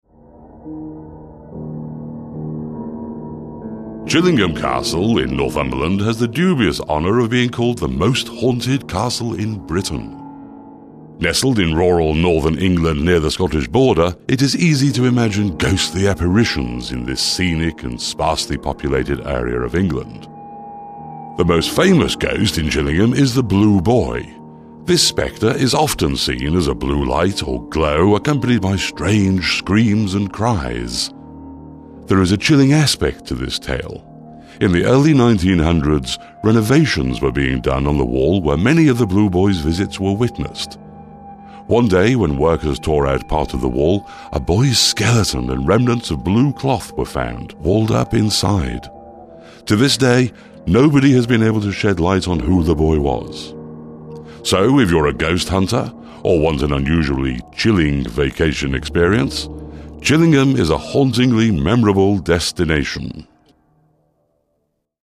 Male
English (British)
Adult (30-50), Older Sound (50+)
Authotitative, commanding, mature, educational, BBC style r/p British English style.
Documentary
All our voice actors have professional broadcast quality recording studios.